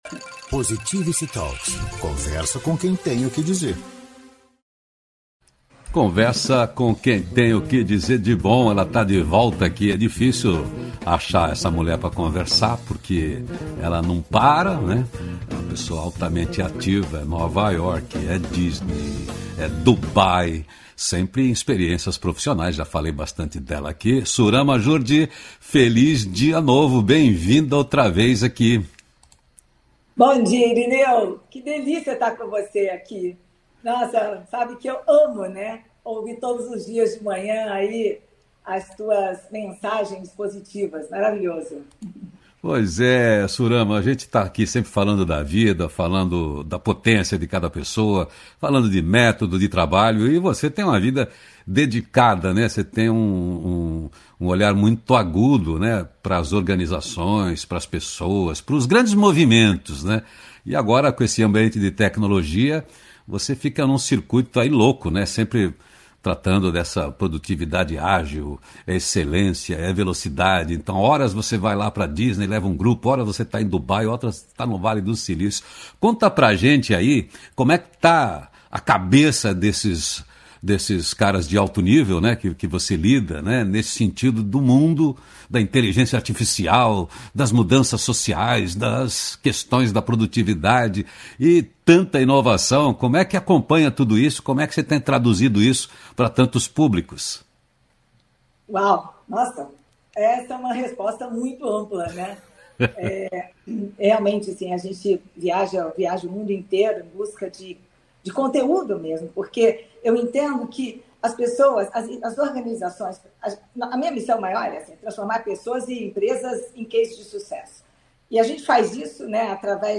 Conversa com quem tem o que dizer de bom.
O programa é um contraponto leve ao noticiário hard predominante na mídia tradicional de rádio e tv. O Feliz Dia Novo, é uma revista descontraída e inspiradora na linguagem de rádio (agora com distribuição via agregadores de podcast), com envolvimento e interatividade da audiência via redes sociais.